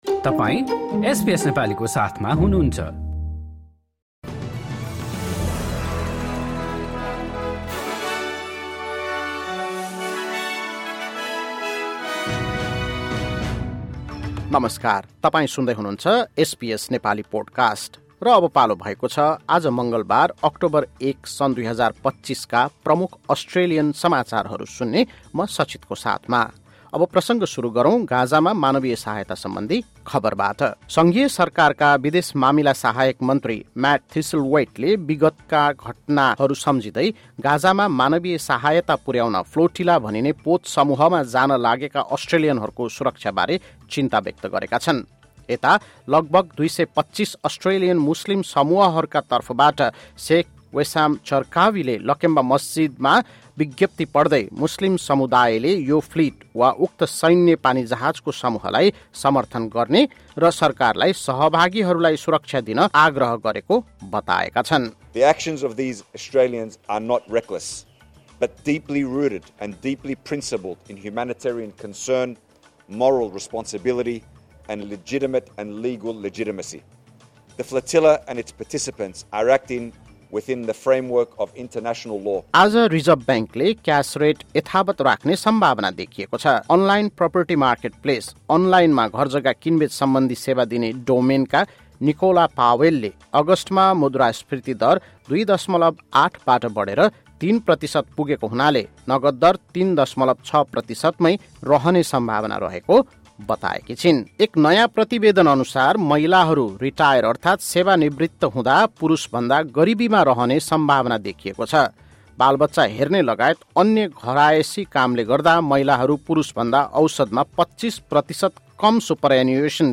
SBS Nepali Australian News Headlines: Tuesday, 30 September 2025